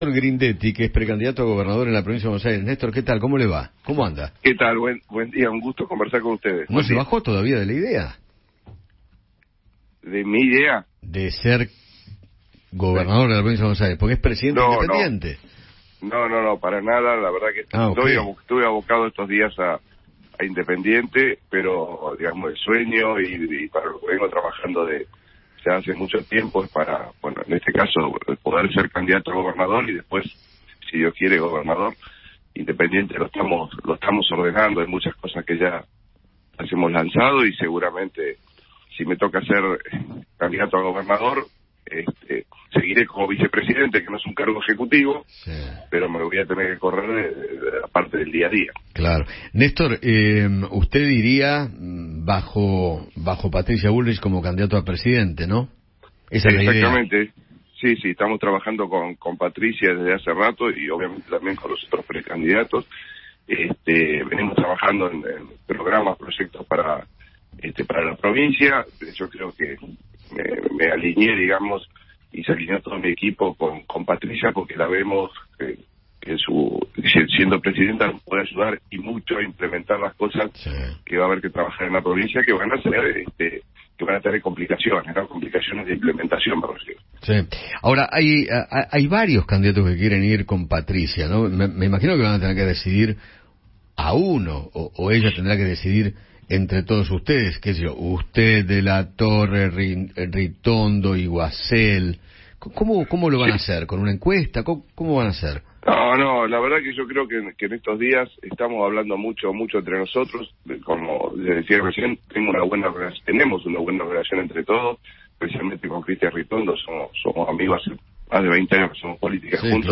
Néstor Grindetti, pre candidato a Gobernador de la Provincia de Buenos Aires por Juntos por el Cambio, conversó con Eduardo Feinmann sobre la campaña electoral de la coalición y analizó su rol en el club Independiente.